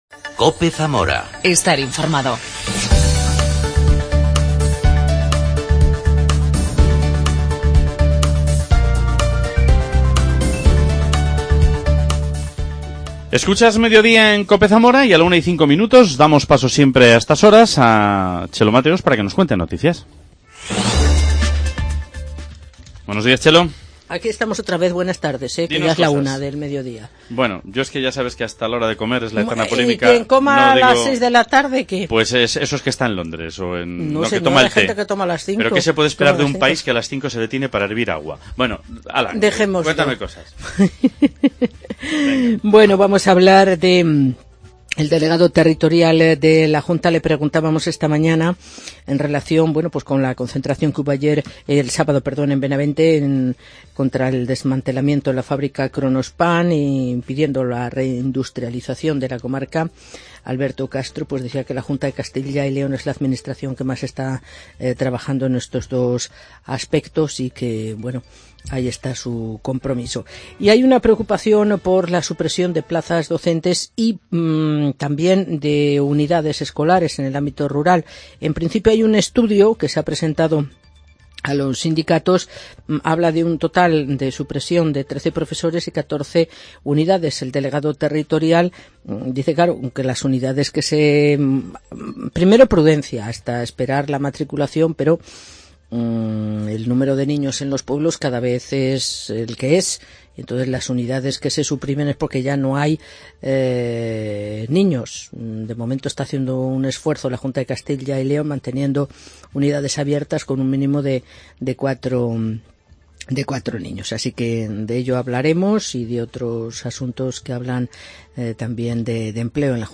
Redacción digital Madrid - Publicado el 06 feb 2017, 14:34 - Actualizado 18 mar 2023, 20:30 1 min lectura Descargar Facebook Twitter Whatsapp Telegram Enviar por email Copiar enlace La actriz Loreto Valverde visita Cope Zamora para hablar del estreno el próximo 18 de marzo de la comedia de Alfonos Paso"Cosas de papá y mamá", que protagonizará en el Teatro Ramos Carrión con María Luisa Merlo y Juan Meseguer.